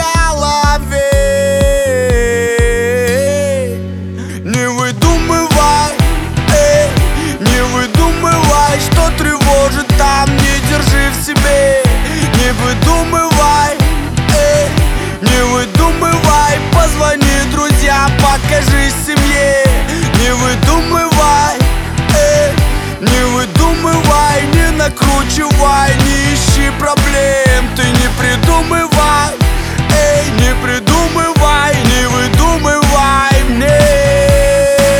Pop Hip-Hop Rap